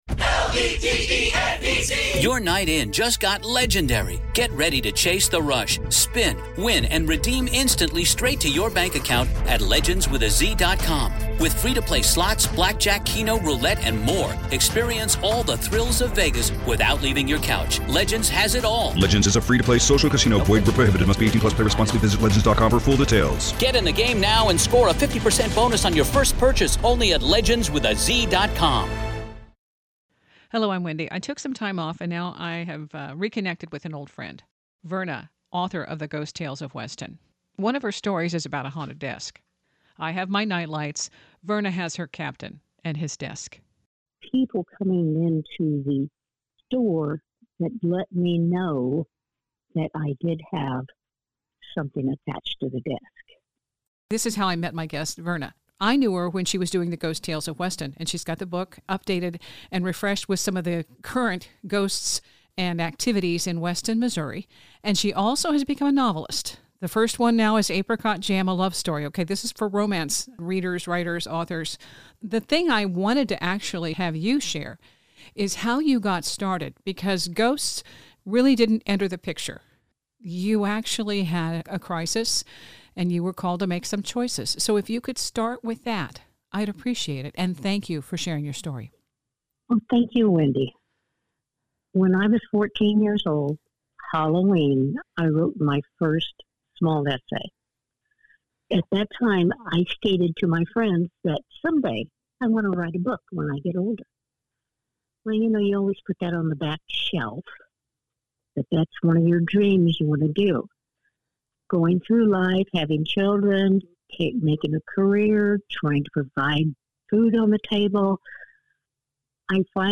EVP? Or not.
I left the little ghostie in.
Nothing else in the audio matches this anomaly. The garbled bits of word salad coincidentally appear when the topic of discussion is The Saint George Hotel visitors seeing something unexplainable in their room overnight.